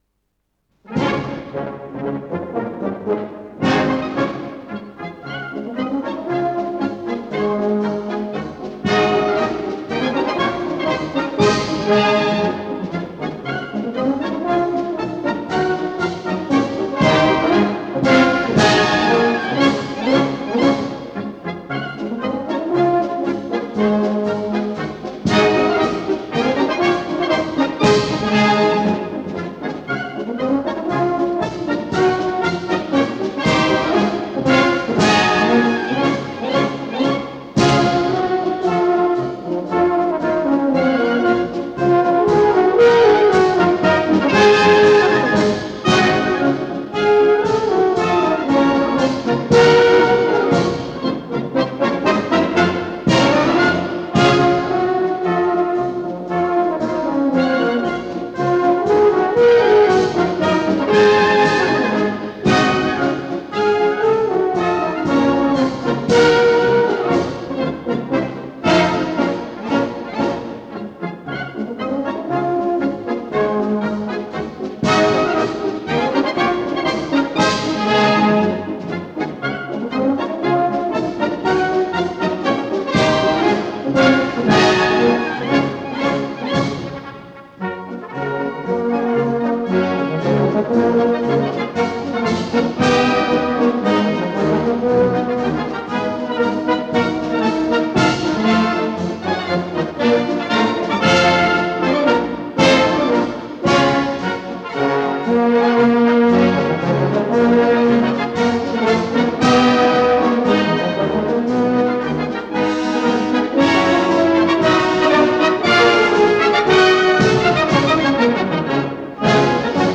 с профессиональной магнитной ленты
РедакцияМузыкальная